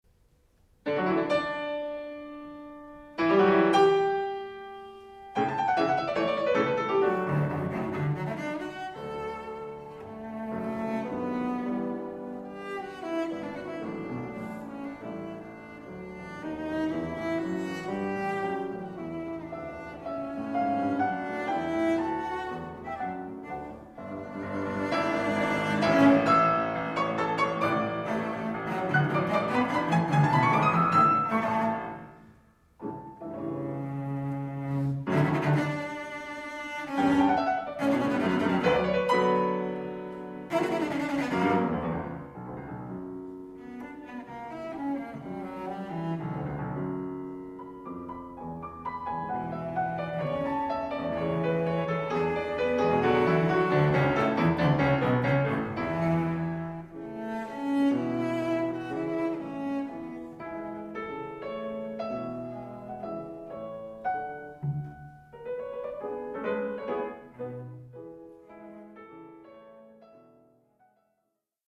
Sonata D-dur na wiolonczelę i fortepian op. 102 nr 2 cz. I Allegro con brio
wiolonczela
fortepian